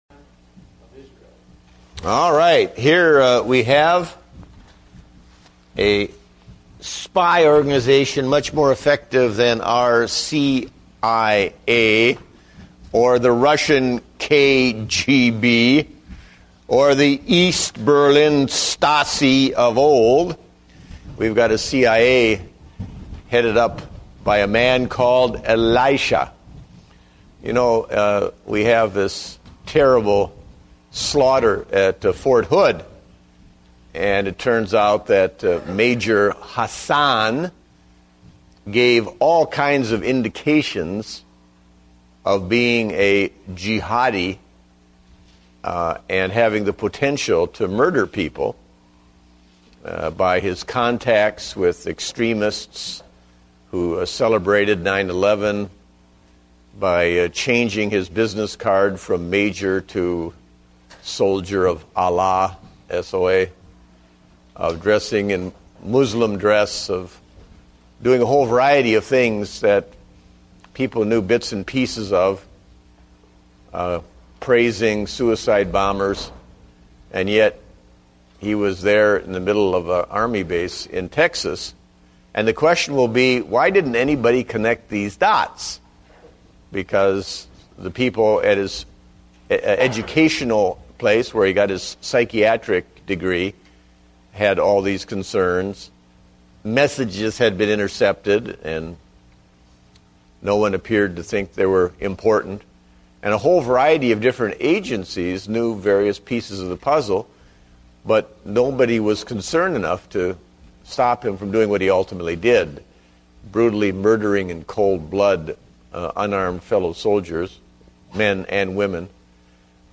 Date: November 15, 2009 (Adult Sunday School)